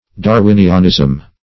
\Dar*win"i*an*ism\
darwinianism.mp3